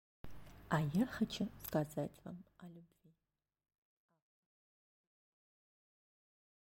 Аудиокнига А я хочу сказать Вам о любви | Библиотека аудиокниг